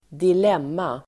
Uttal: [²dil'em:a]